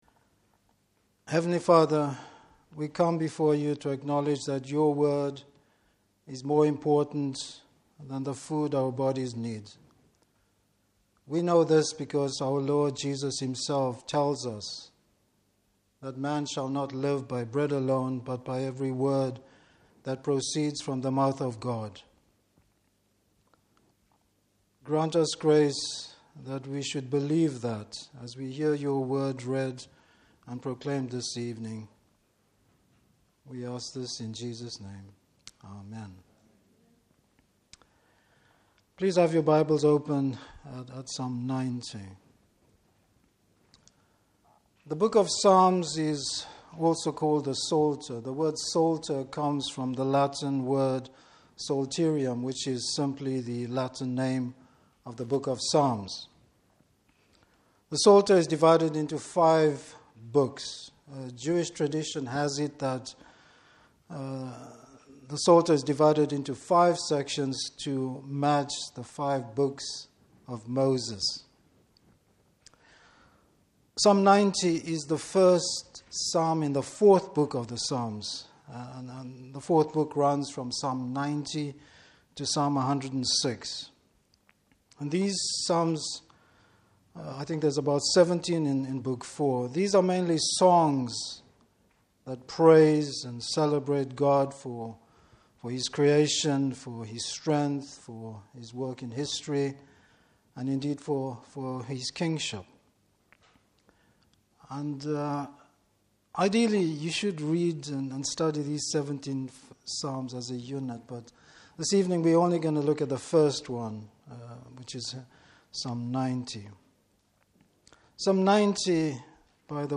Service Type: Evening Service Moses compares Man’s plight with God’s character and power!